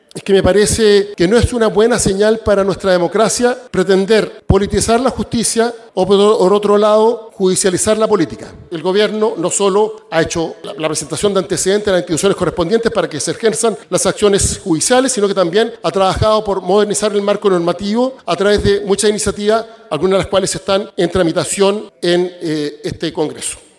El caso ProCultura, sin dudas, ha agudizado la tensión entre oficialismo y oposición, lo que quedó demostrado la tarde de este lunes, cuando se llevó a cabo una tensa sesión especial en la Sala de la Cámara de Diputados y Diputadas, que terminó con la acusación de una supuesta agresión entre parlamentarios y múltiples dardos cruzados.